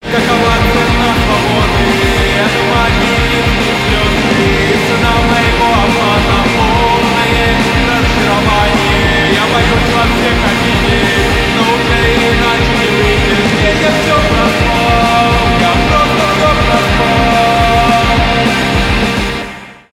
рок
indie rock , быстрые